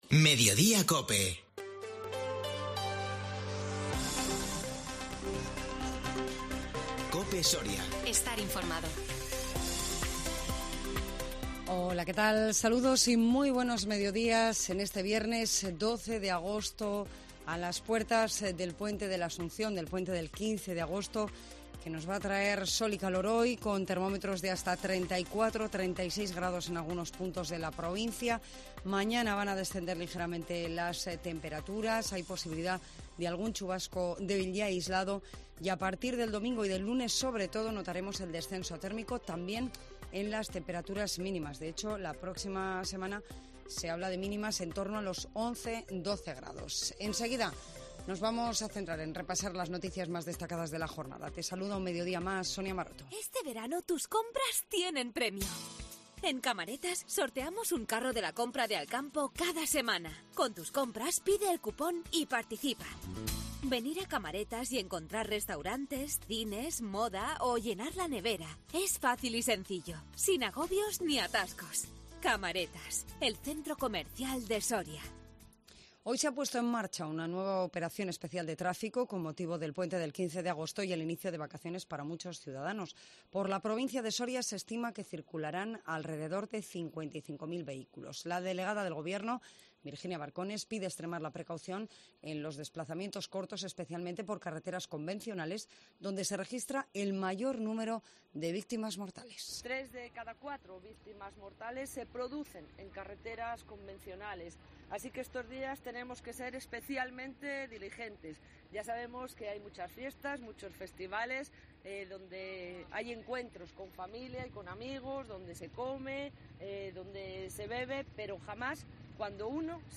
INFORMATIVO MEDIODÍA COPE SORIA 12 AGOSTO 2022